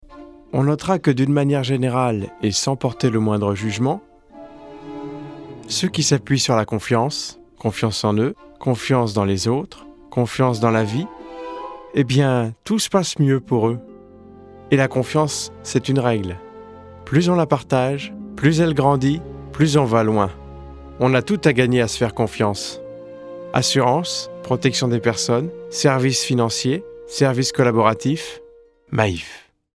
pub maif